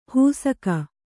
♪ hūsaka